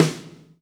snare 7.wav